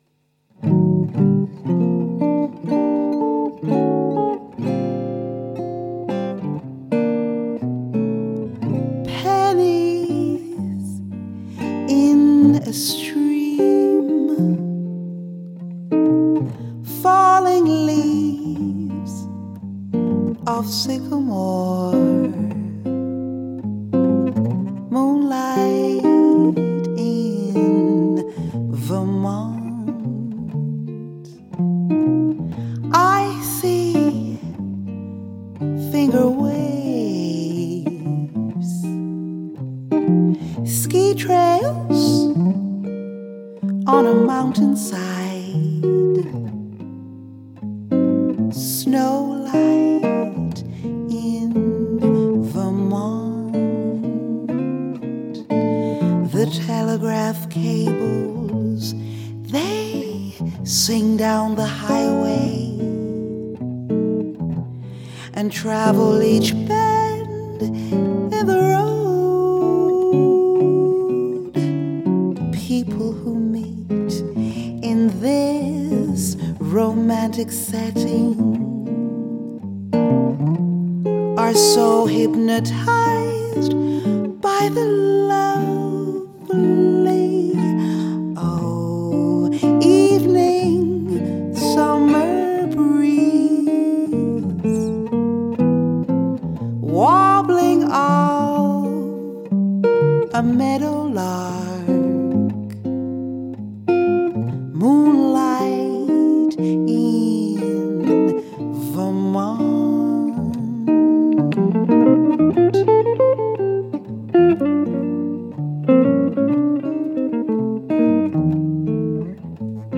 Gitarre/Loops
Jazzgesang & Jazzgitarrenspiel vom Feinsten